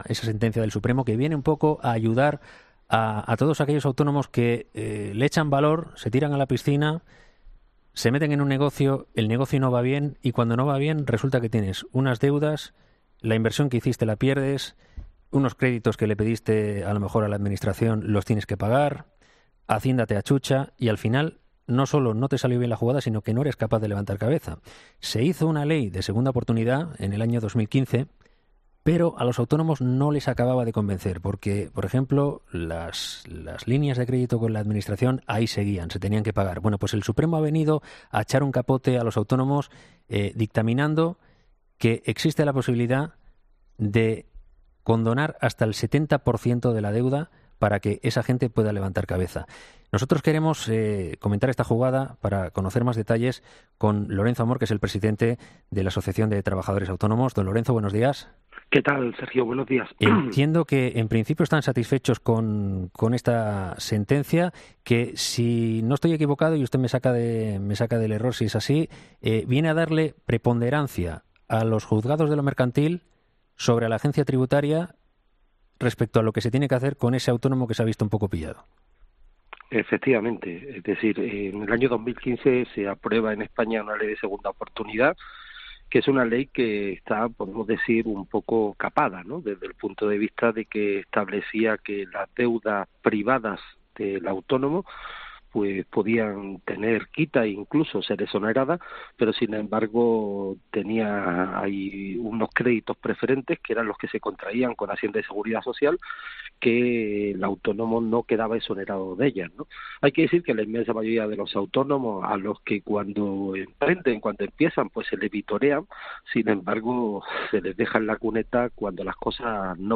Para conocer el fallo, este martes ha sido entrevistado en 'Herrera en COPE'